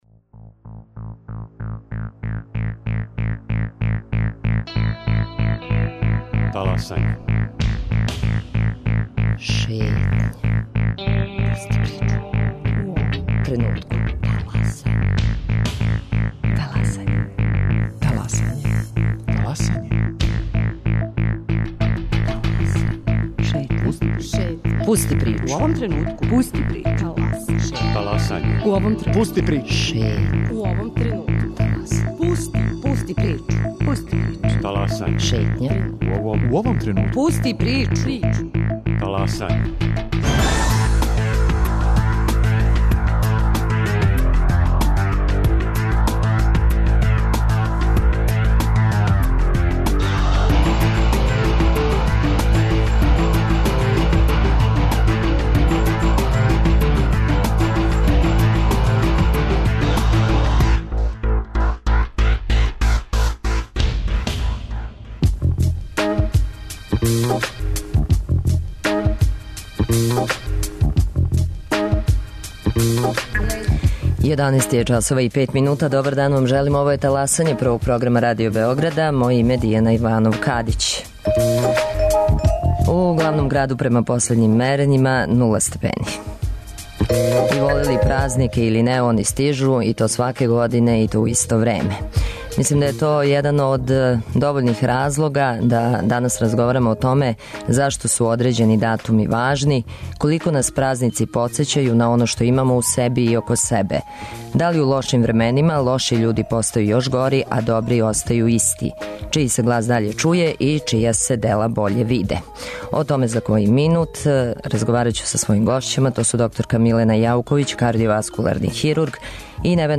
кардиоваскуларни хирург
психотерапеут.